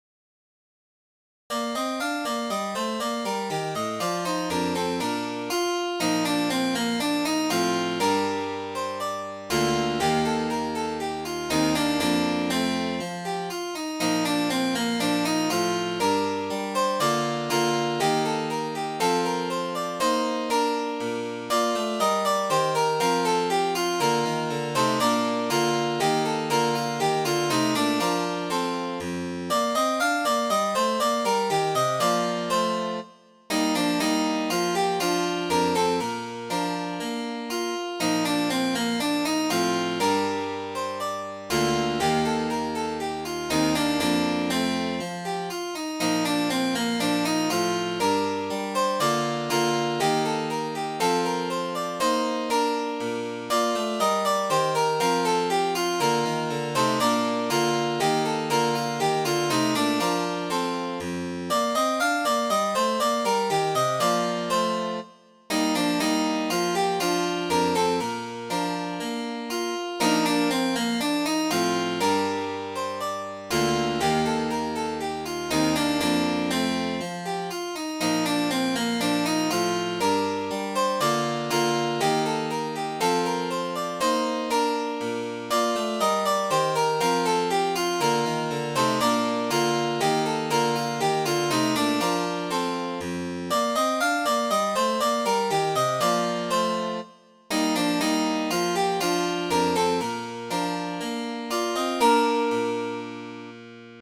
Although this is not verified, the tune has a definite Italian flavor.